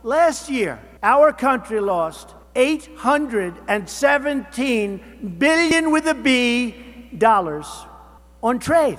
During a VFW National Convention among 4,000 members in Kansas City, Trump says America’s trade deals with other countries are a disaster.
During a VFW National Convention at Bartle Hall in Kansas City, Trump said the biggest winners in the U.S. trade war will be farmers.